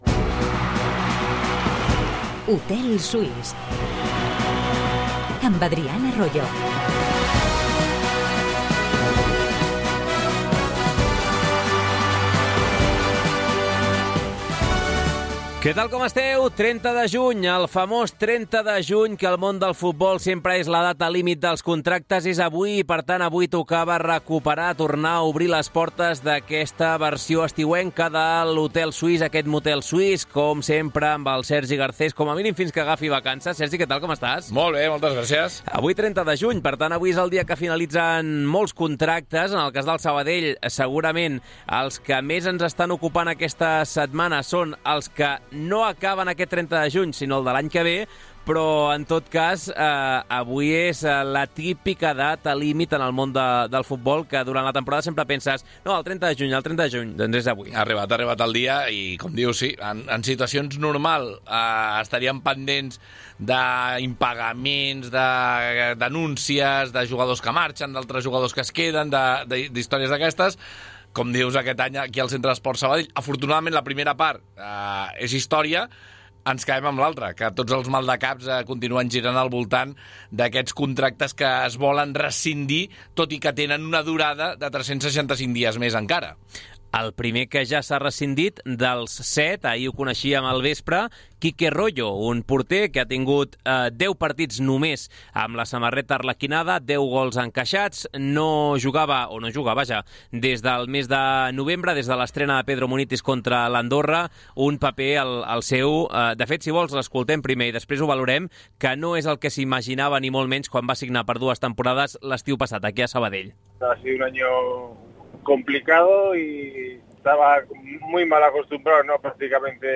Les tertúlies esportives del recordat Hotel Suís de Sabadell prenen forma de programa de ràdio.